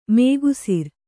♪ mēgusir